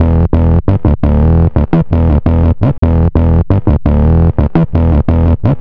08_Fibre_Diet_170_Emin.wav